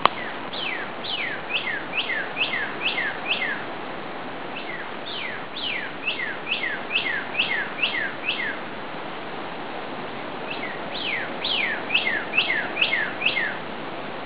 Northern Cardinal